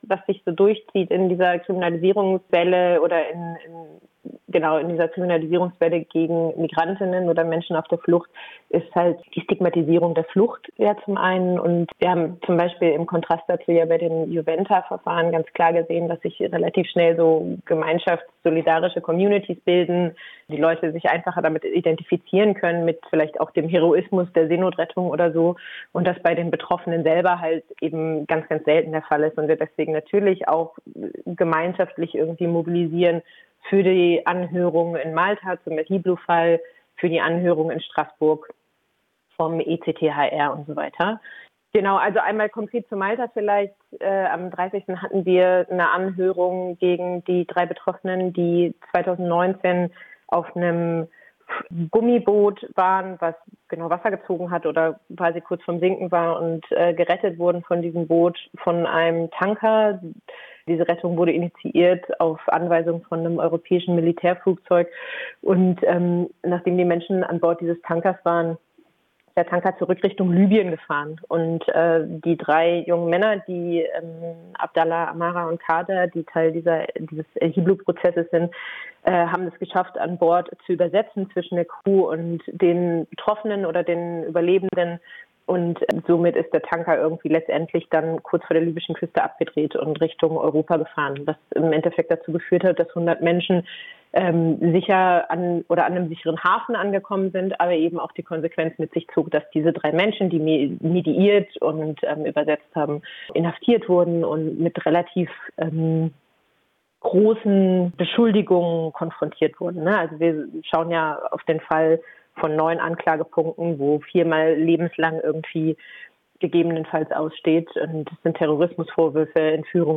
Rede & Aufruf Feministische Aktion 8. März: 7:14
Grußworte Feminism Unstoppable München: 0:53